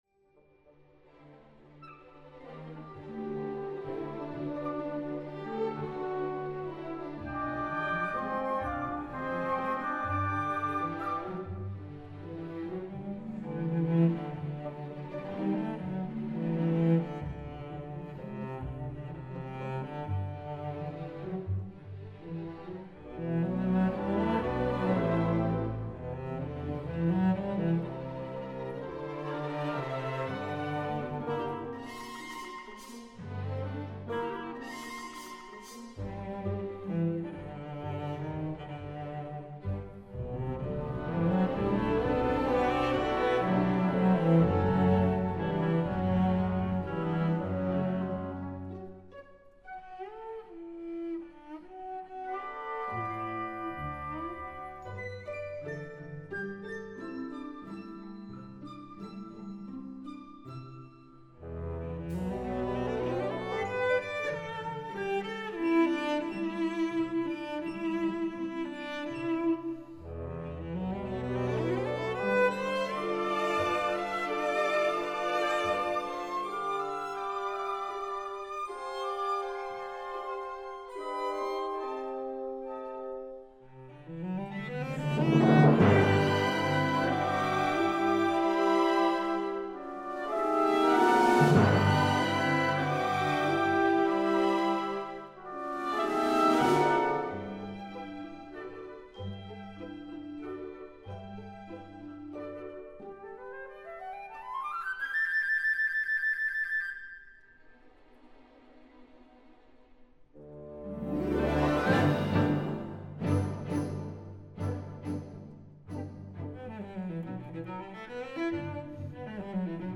Violoncello